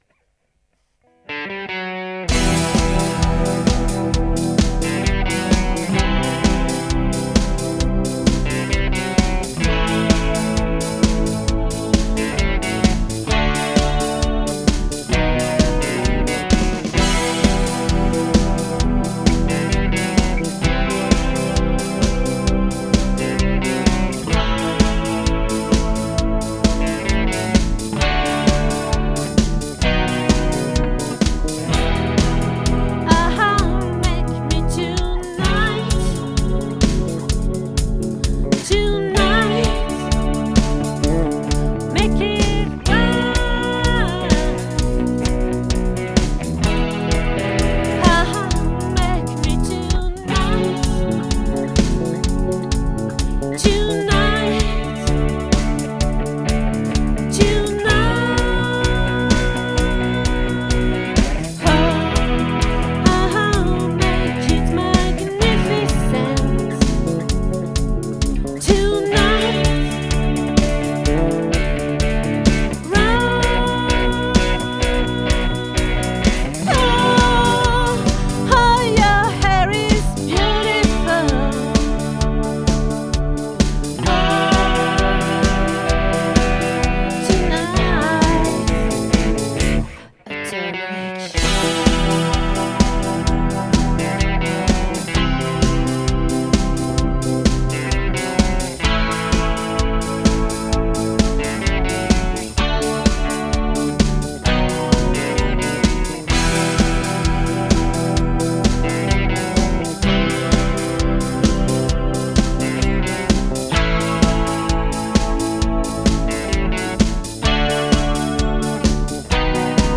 🏠 Accueil Repetitions Records_2022_02_17